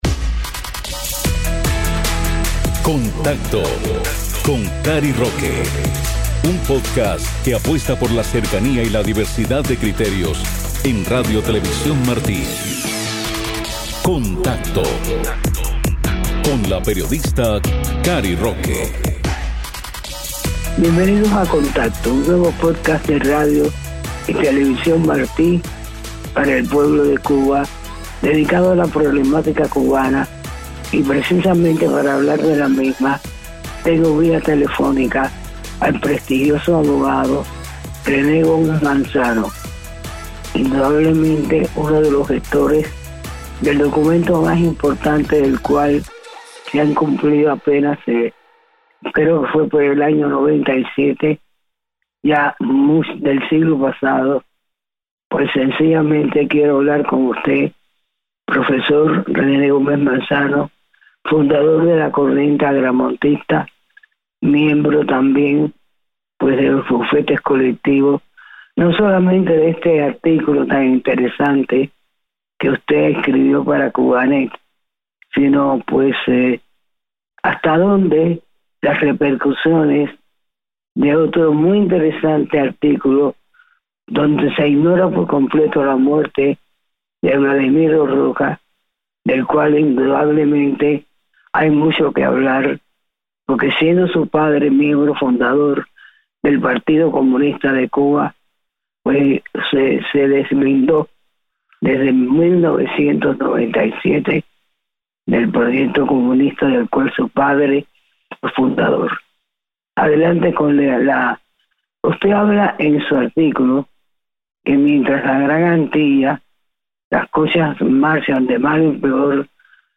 conversan sobre la situación actual de Cuba